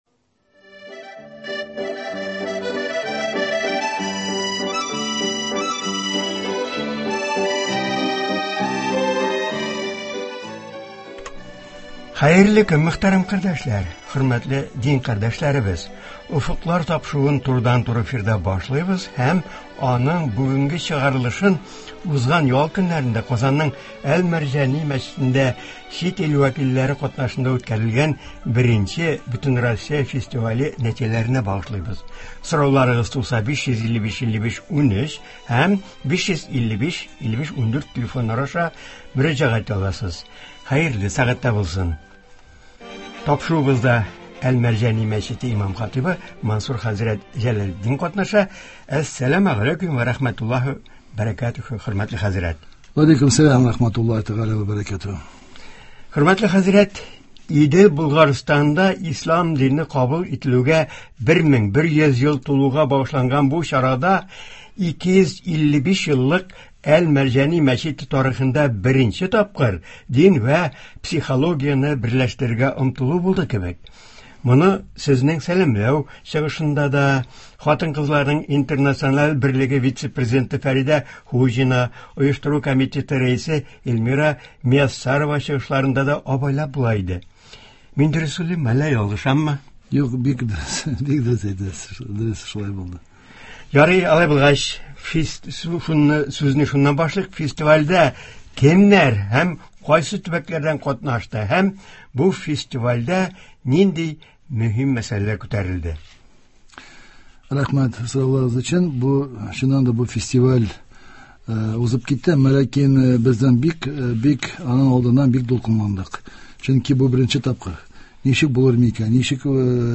Болар хакында турыдан-туры эфирда